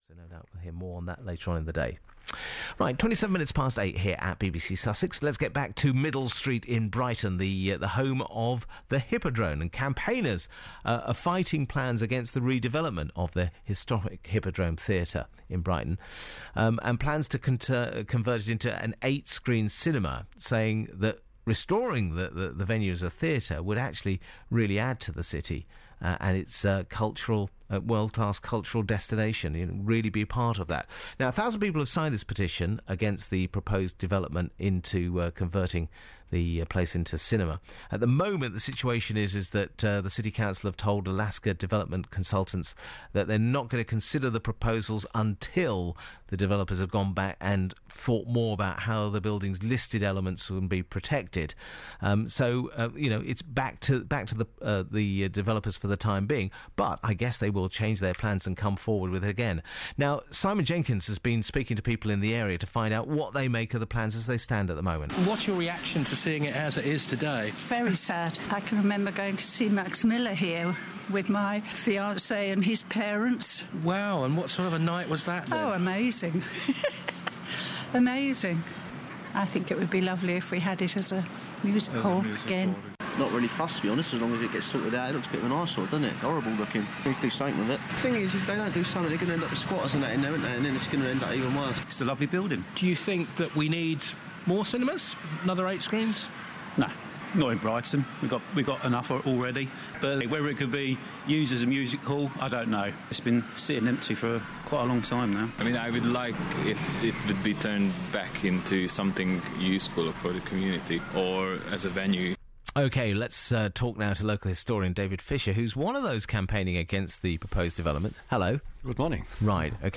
Breakfast Show